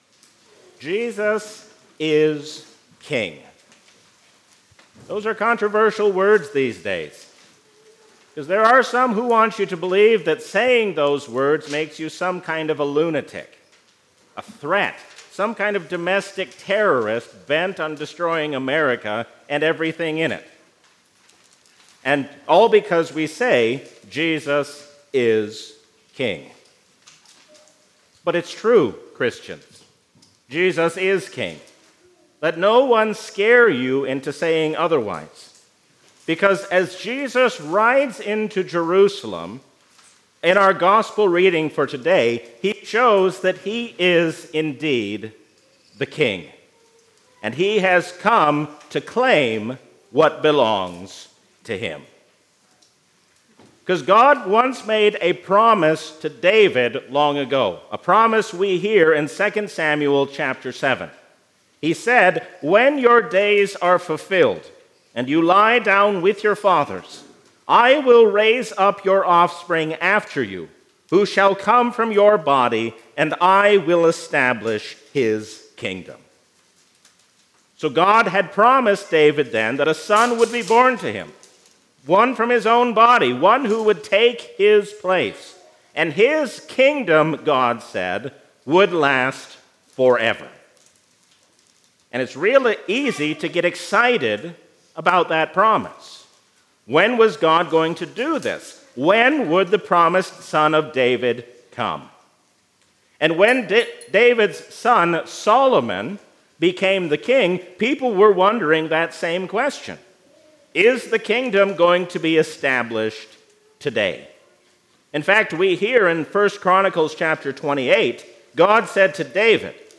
A sermon from the season "Trinity 2024." The ascension of Christ strengthens us to carry out the work of the Church while we wait for His coming.